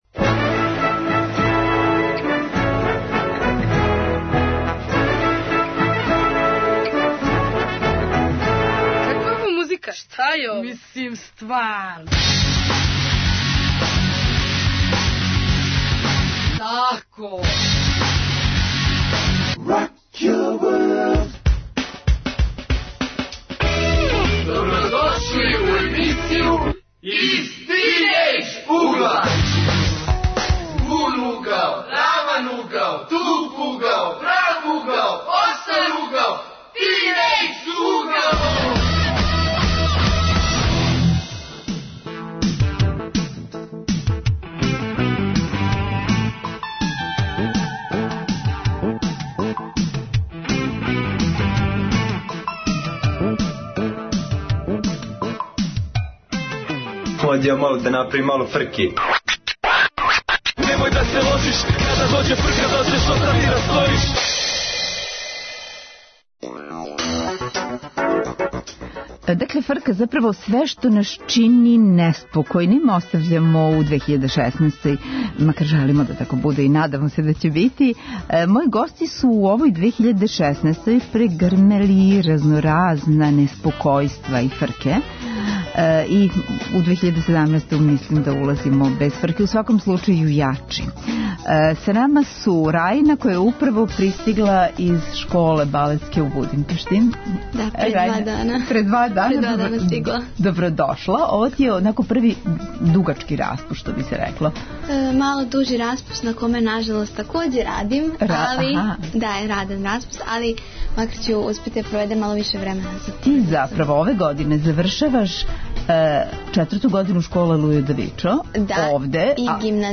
Гости: средњошколци (учесници регионалног такмичења средњошколаца у предузетничким вештинама - пословни изазов). Редовне рубрике, провод за џ, из угла Гугла ...